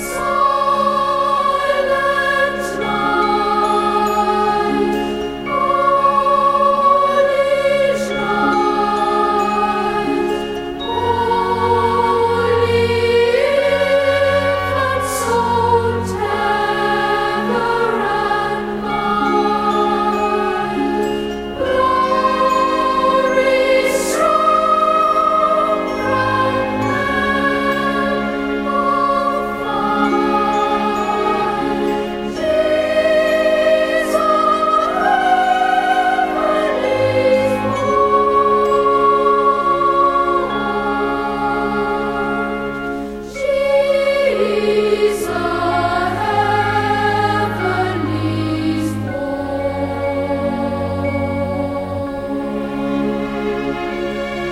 Don't worry - nothing offensive or loud. Just Christmassy.